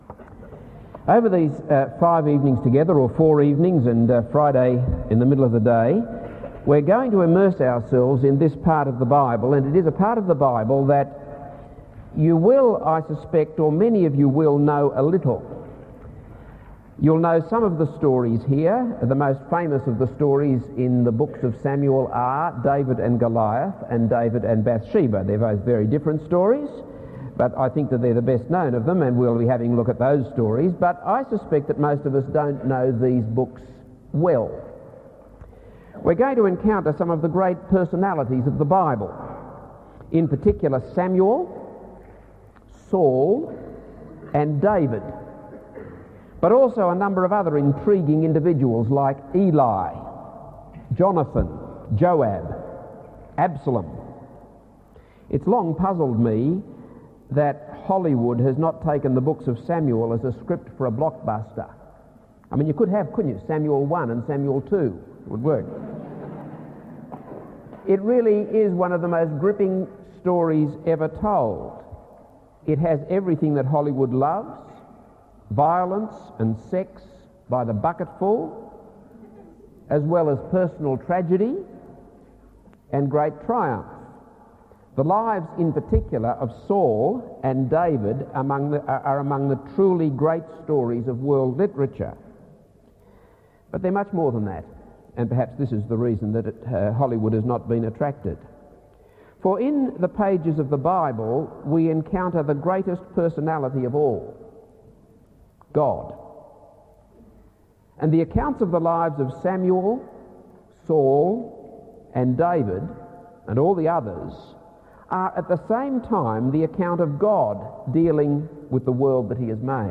This is a sermon on 1 Samuel 1.